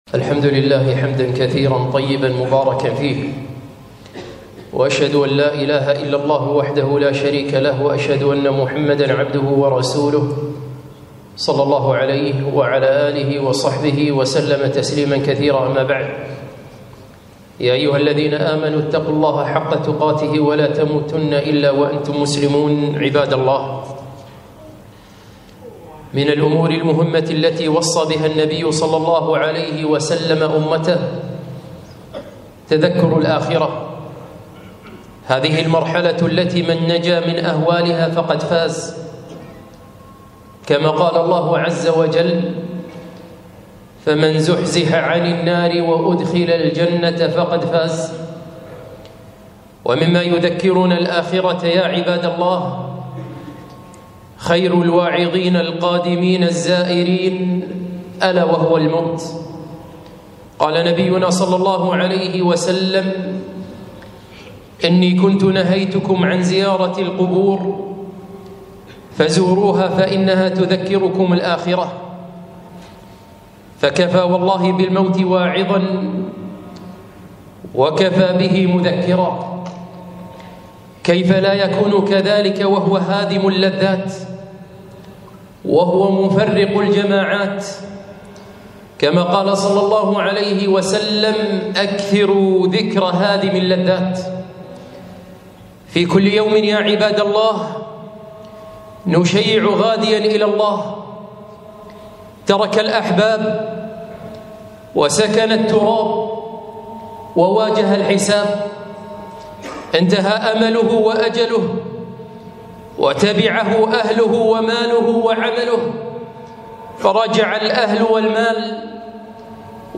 خطبة - الموت وما أدراك ما الموت؟